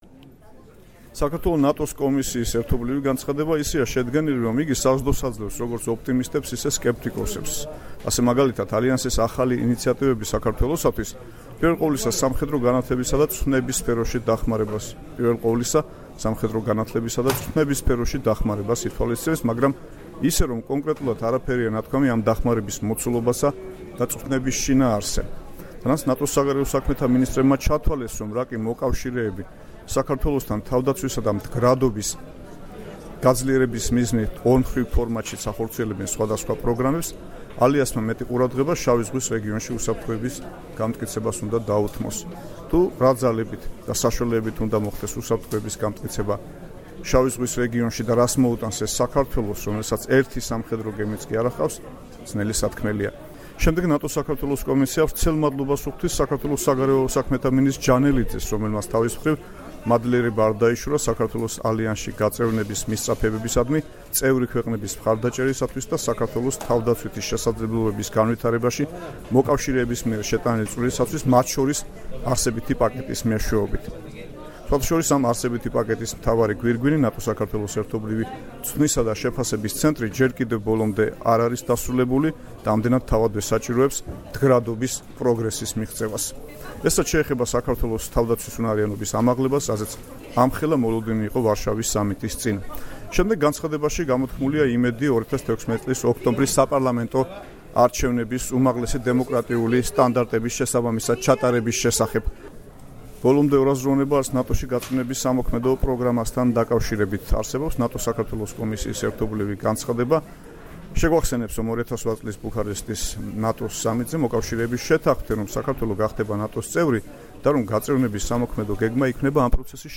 რეპორტაჟი ვარშავიდან